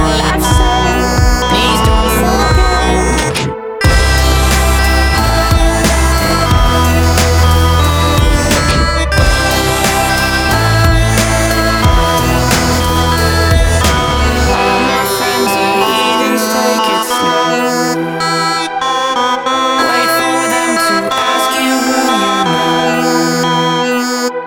• Качество: 320, Stereo
мужской вокал
Electronic
club
Psybient
Psy Chill